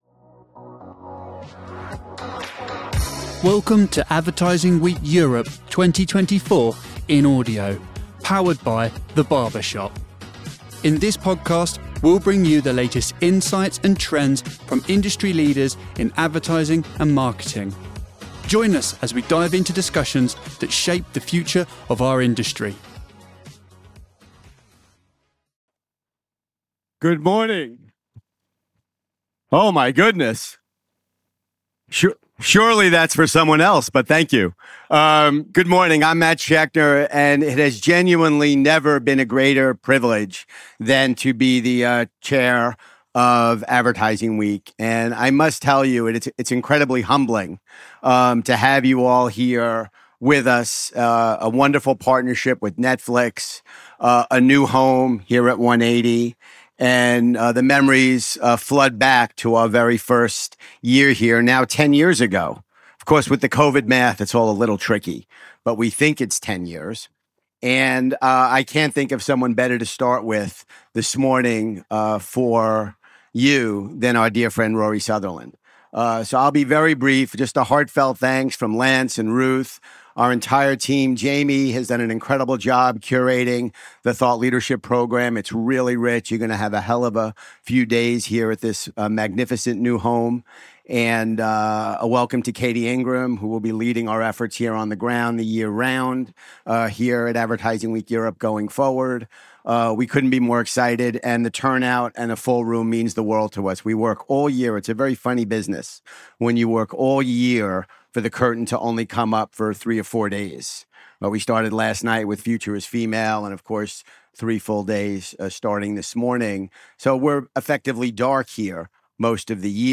Rory Sutherland unveils a forgotten secret in advertising from the past 80 years, arguing that solving global issues requires more than just individual brand efforts. In this insightful talk, Sutherland emphasizes the need for a collective approach in branding to address broader societal challenges. He delves into historical advertising practices, discussing their evolution and presenting strategies for modern impactful branding.